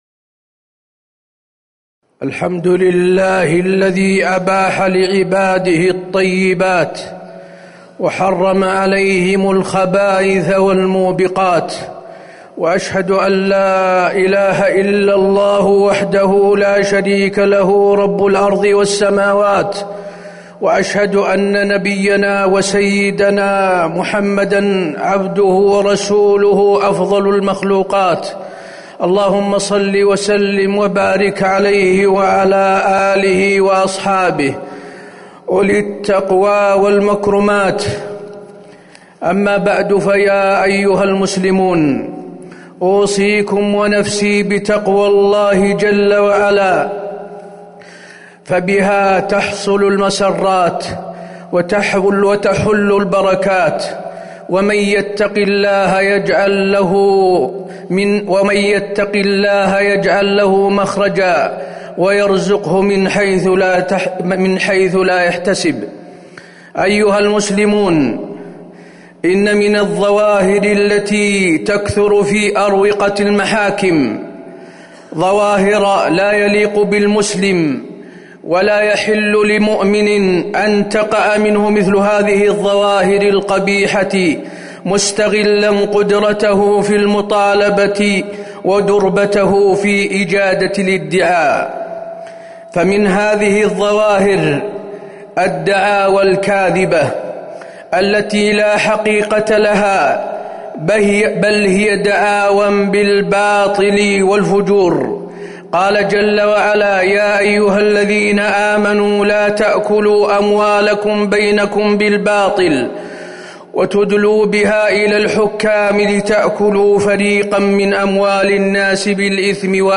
تاريخ النشر ٢٧ جمادى الآخرة ١٤٤١ هـ المكان: المسجد النبوي الشيخ: فضيلة الشيخ د. حسين بن عبدالعزيز آل الشيخ فضيلة الشيخ د. حسين بن عبدالعزيز آل الشيخ ظواهر سلبية لا تليق بالمسلم